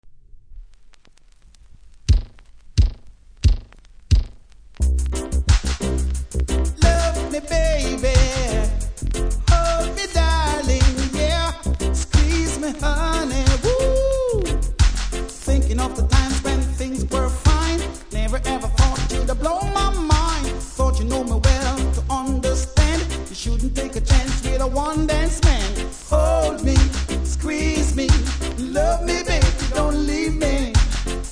多少ノイズありますがプレイは出来るレベルだと思いますので試聴で確認下さい。